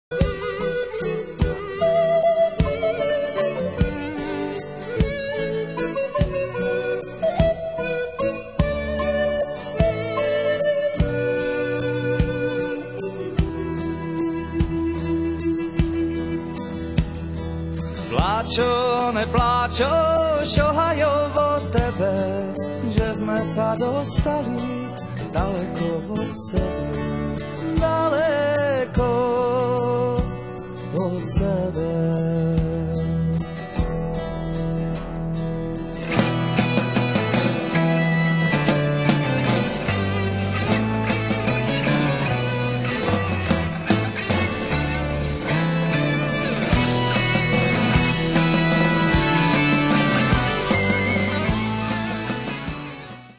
jedna z nejzajímavějších skupin české folkrockové scény.
moravská lidová